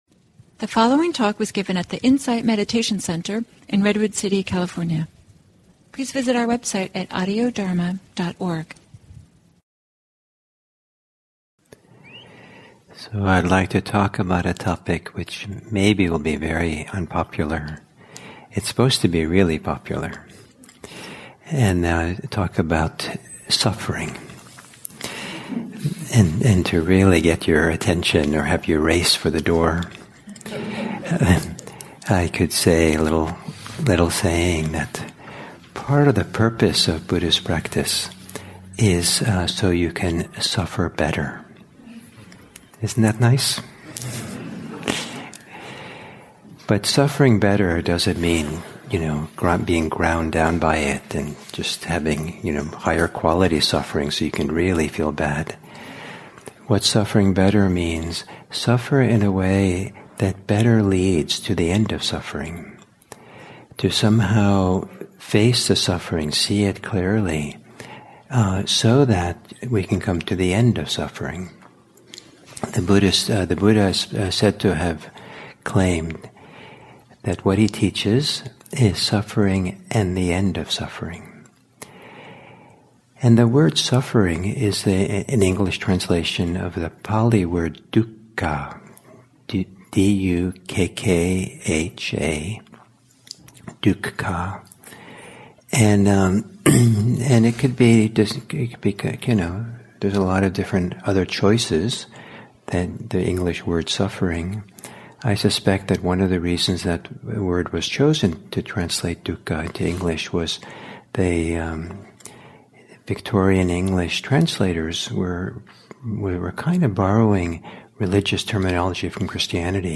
at the Insight Meditation Center in Redwood City, CA.